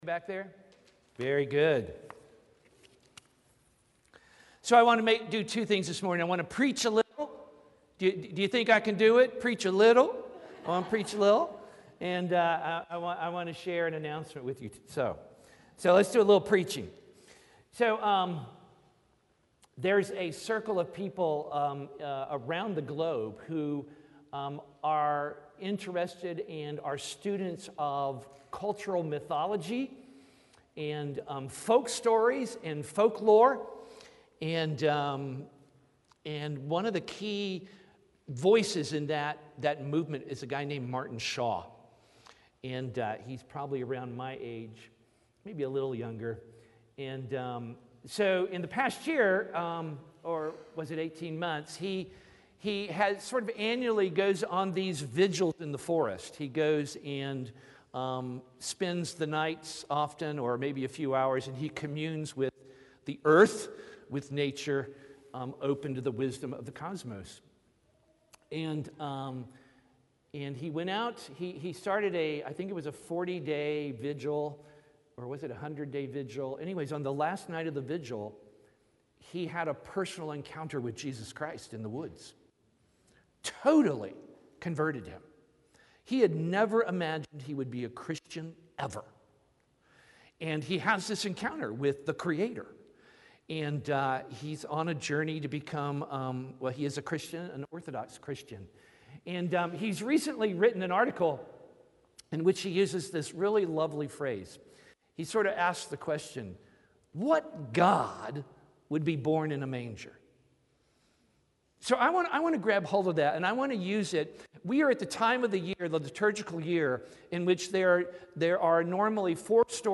Sermon 1/8/2023 The First Sunday of Epiphany